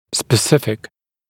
[spə’sɪfɪk][спэ’сифик]определенный, специальный, особый, специфический